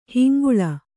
♪ hinguḷa